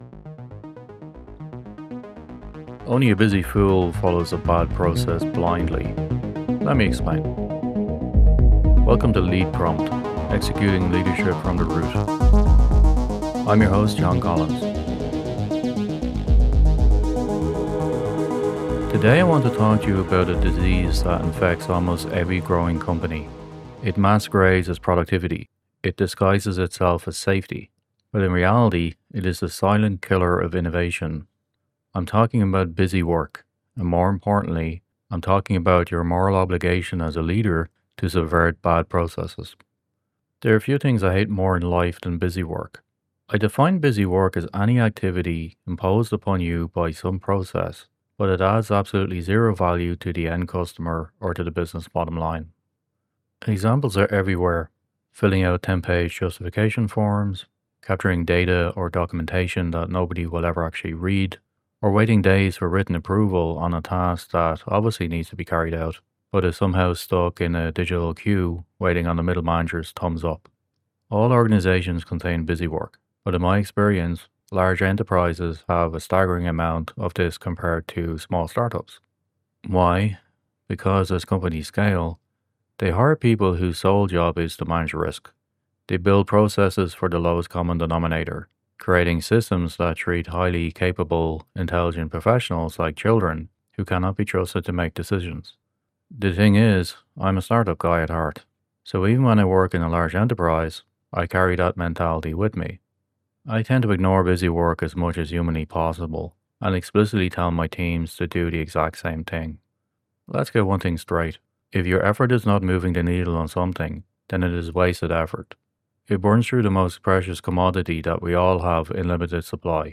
Title music